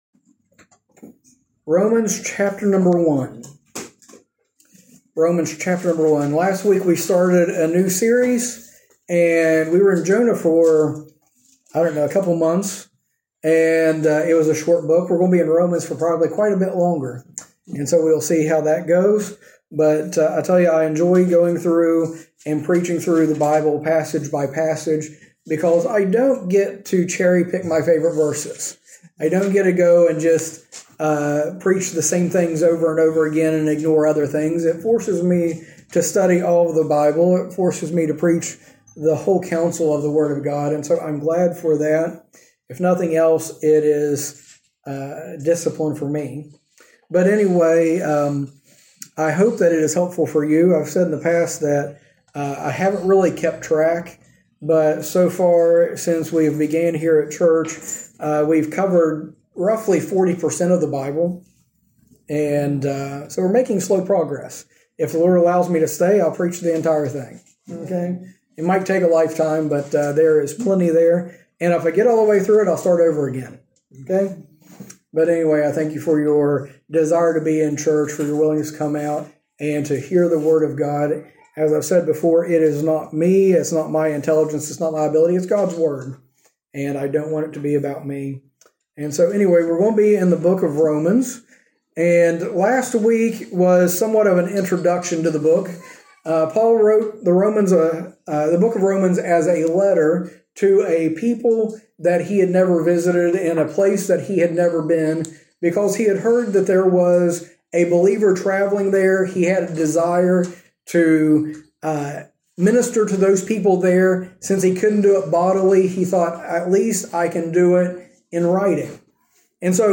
In this sermon series, we go through Paul's letter to the Romans section by section as he shows how the Christian life should be.